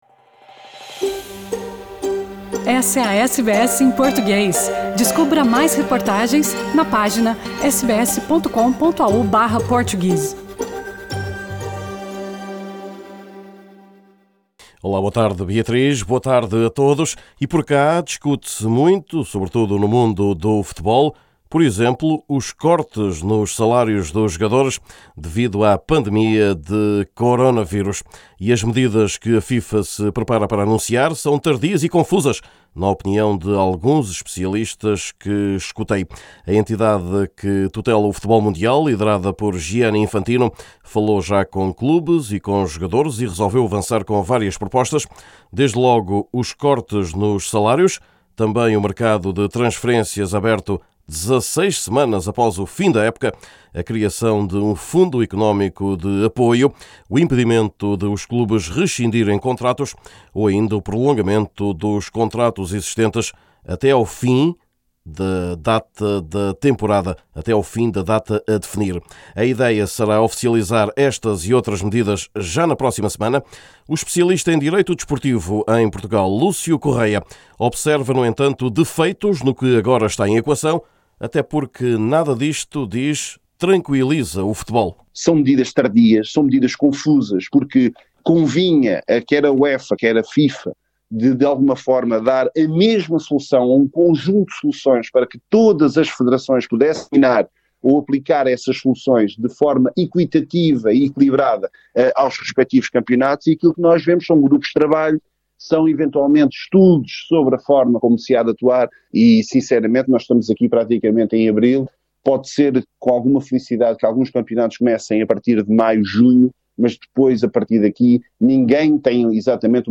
Neste boletim semanal, dominado ao nível do desporto pela pandemia da Covid-19, o exemplo de um treinador luso “lá fora”, onde o campeonato igualmente está parado – Moçambique.